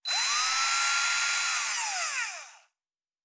Servo Small 2_2.wav